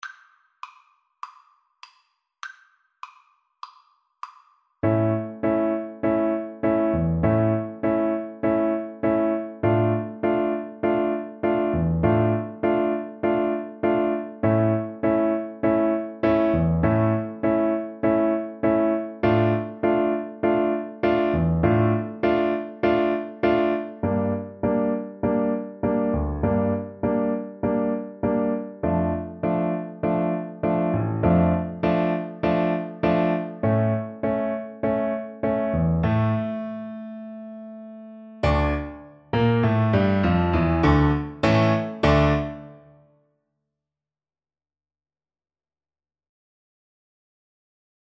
Tempo di Tango